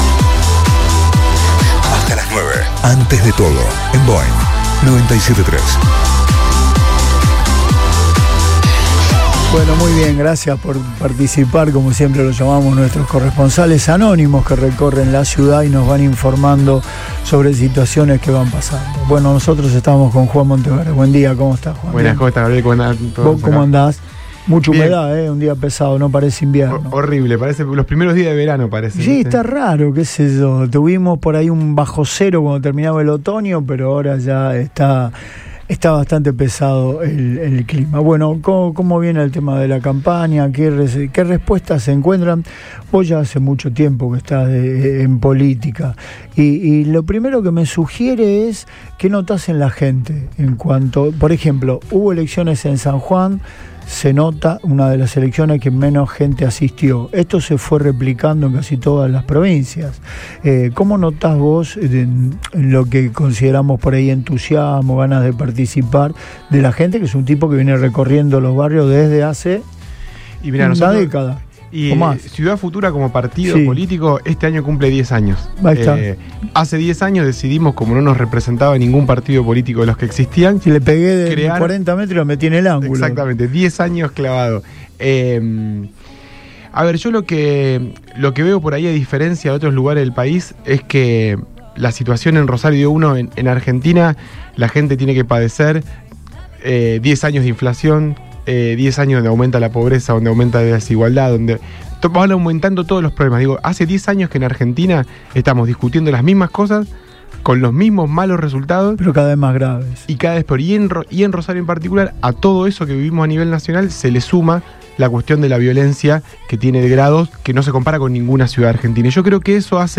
Juan Monteverde, precandidato a intendente de Rosario, visitó los estudios de Radio Boing